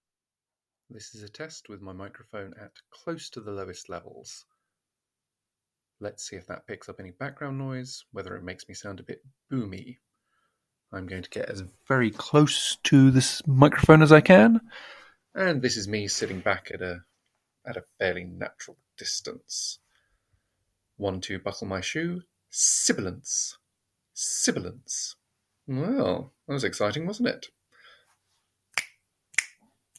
The microphone is ridiculously sensitive. I had to set it to the lowest level otherwise it would pick up sounds from other rooms and made me sound a bit boomy.
mictest.mp3